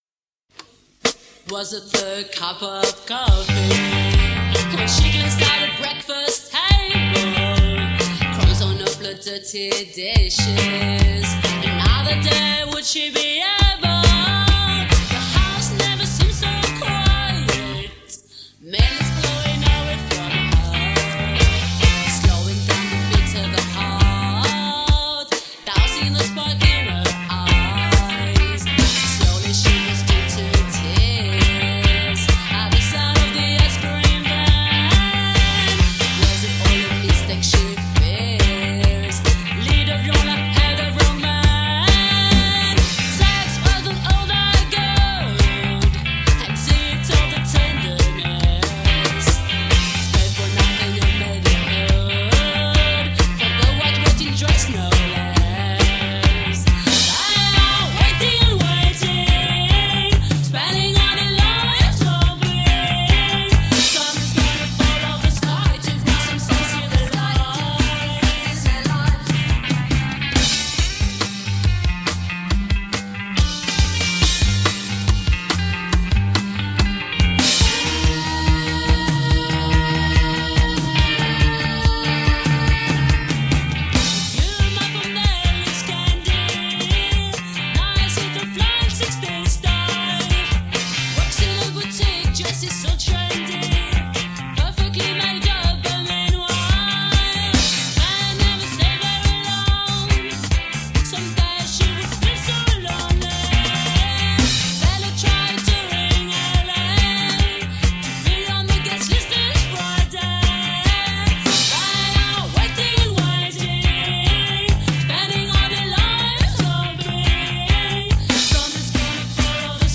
post-Indie all-girl supergroup.
guitarist